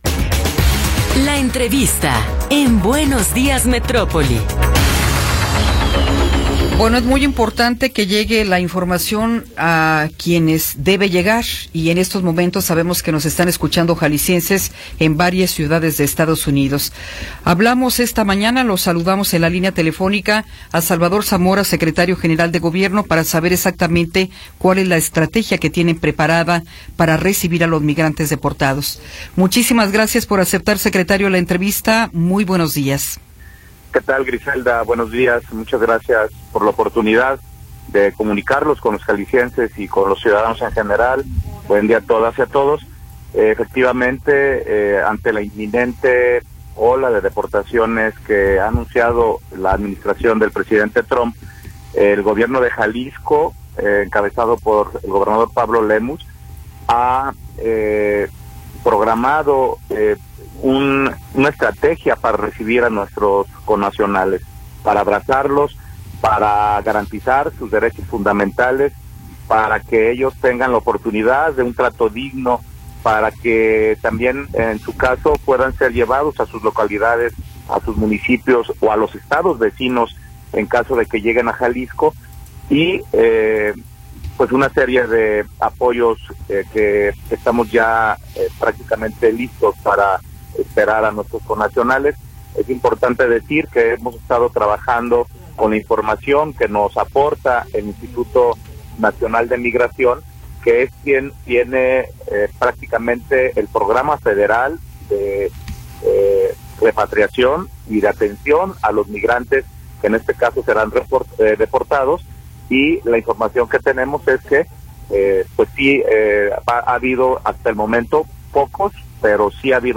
Entrevista con Salvador Zamora
Salvador Zamora, secretario general de gobierno del estado de Jalisco, nos habla sobre la estrategia para la atención de migrantes deportados.